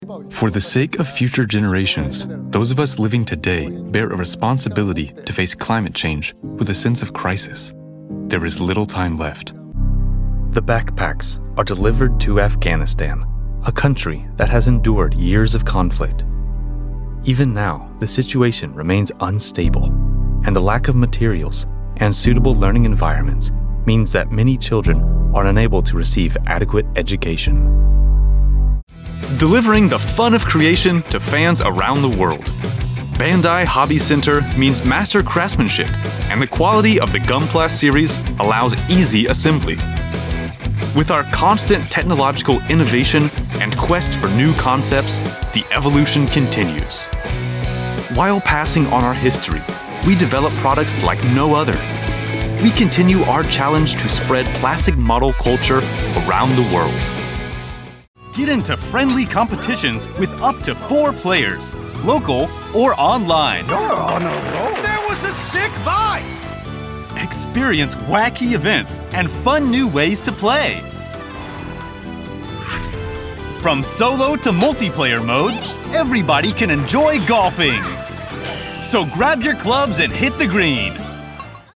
Energetic, Youthful, and Genuine American voice in Tokyo, Japan
Dubbing, Documentary, and Upbeat Promos
Serious Dubbing, Documentary, and Upbeat Promos.mp3